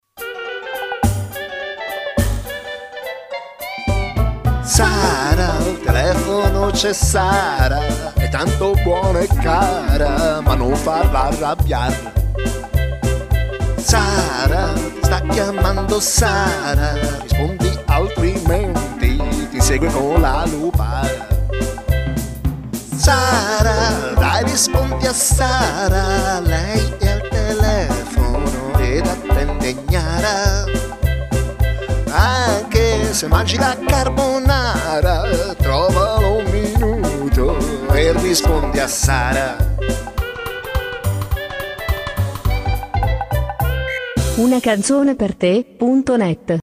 Una suoneria personalizzata